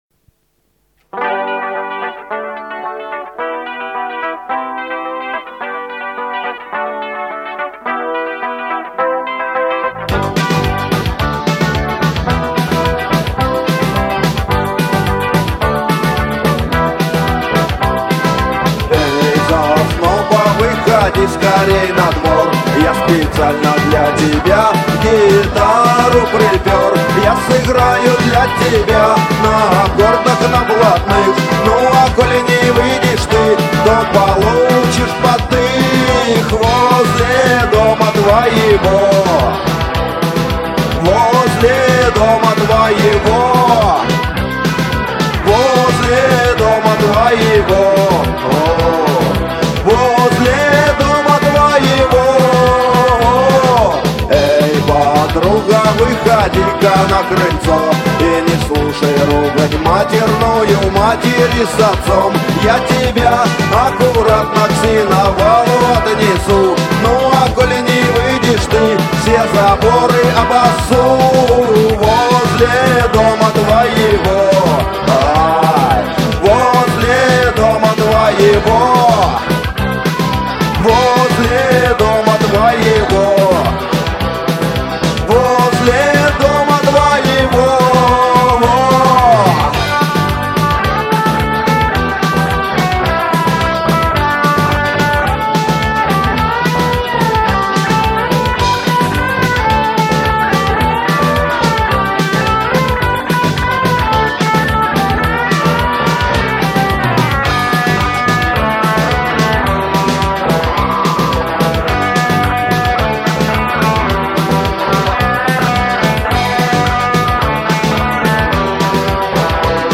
Жанр: Панк рок